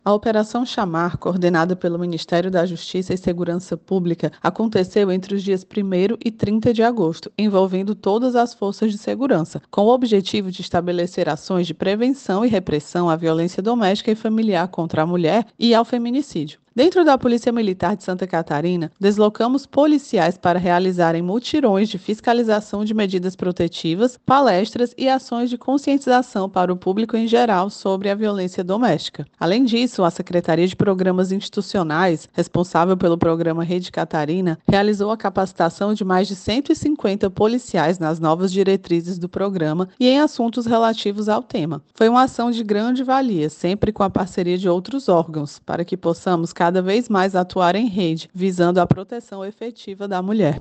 SECOM-Sonora-Participacao-PMSC-Operacao-Shamar.mp3